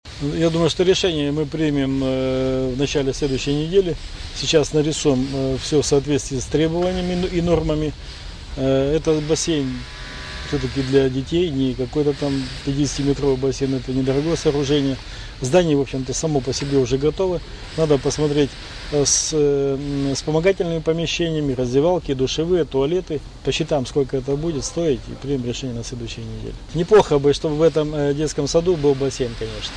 У детского сада на Горького, 28 в Вологде может появится собственный бассейн. О такой возможности во вторник, 21 августа, журналистам рассказал Глава города Евгений Шулепов, сообщает ИА «СеверИнформ — Новости Вологды».